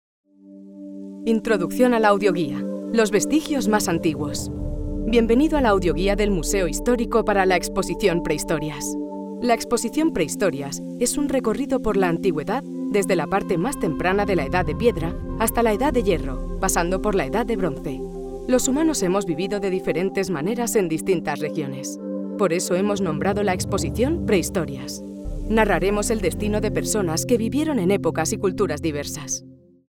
Audio Guides
European Spanish female voice over talent at your disposal!
I have a current, relatable, on trend voice, that is bright, clear, informative and engaging.
Iberian Spanish accent. 🙂